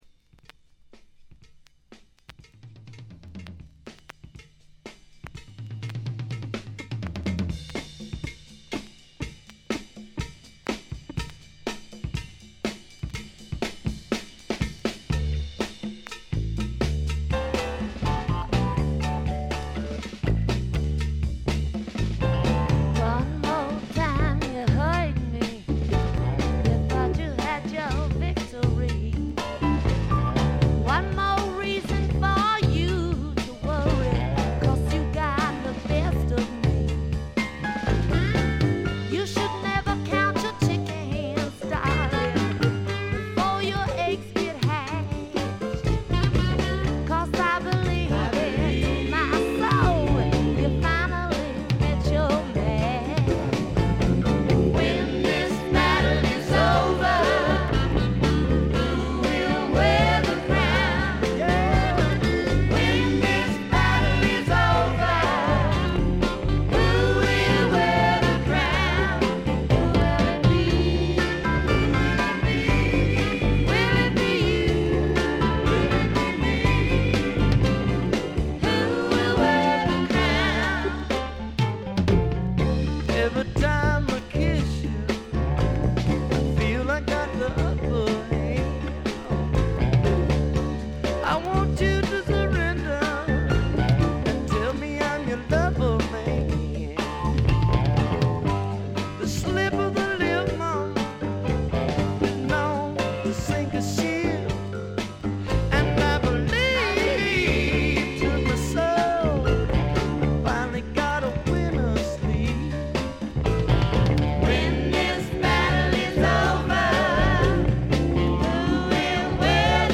ところどころで軽微なチリプチ。
まさしくスワンプロックの原点ともいうべき基本中の基本盤。
試聴曲は現品からの取り込み音源です。